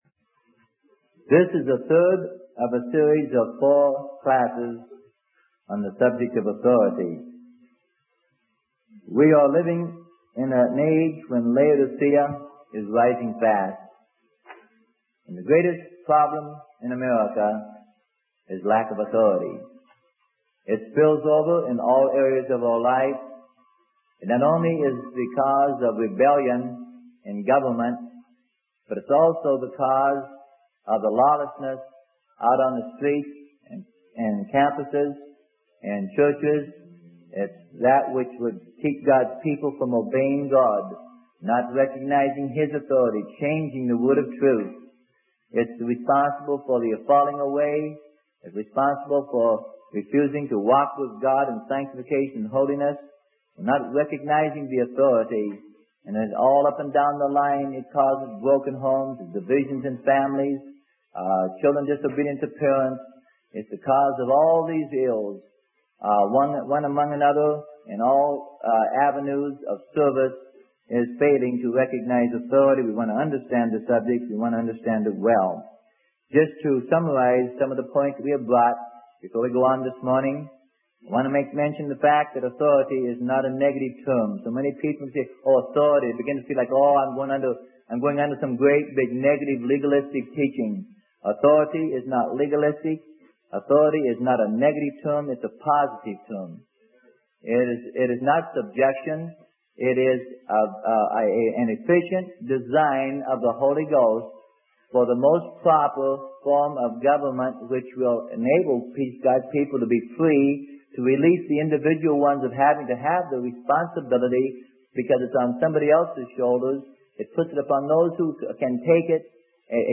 Sermon: Authority - Part 3 - Freely Given Online Library